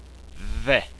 DH - as in that